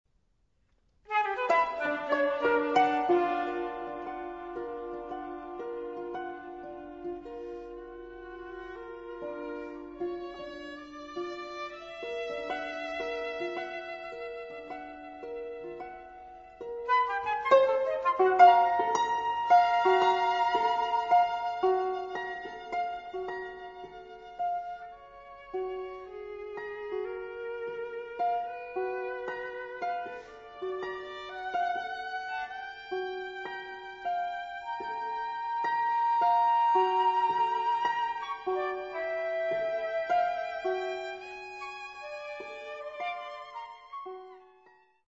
Flute
Harp
Viola